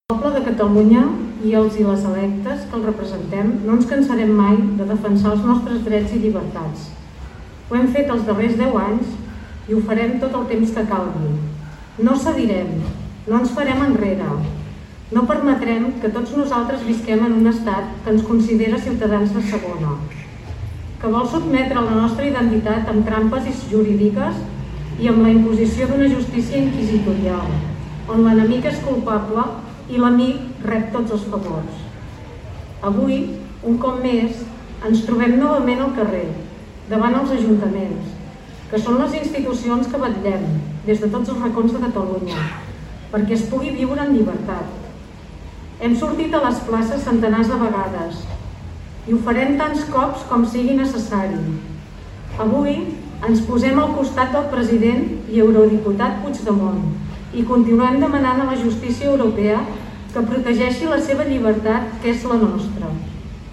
A Tordera, prop d’una trentena de persones es van reunir a la Plaça de l’Ajuntament per aquest motiu. L’ANC Tordera i Òmnium Cultural van ser els encarregats de llegir el manifest.